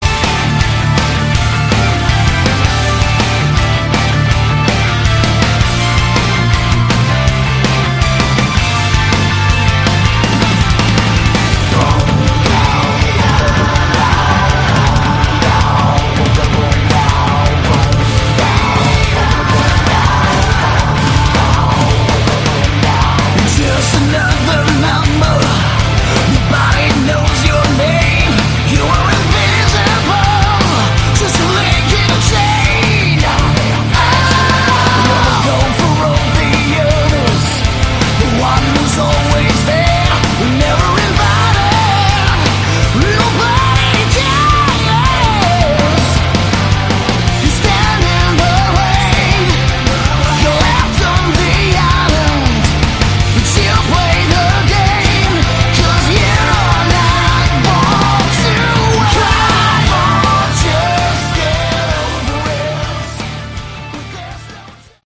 Category: Melodic Metal
guitars
vocals
bass
keyboards
drums